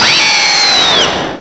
cry_not_zebstrika.aif